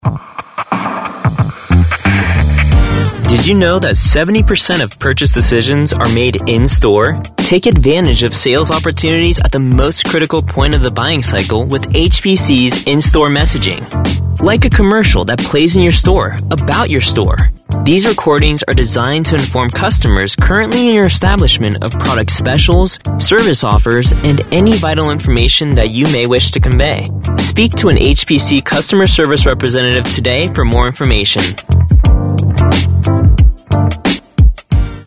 Professional Voice Talent Choices
Male English Voices